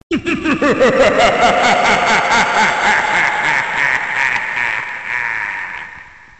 Другие рингтоны по запросу: | Теги: смс, SMS, смех, зловещий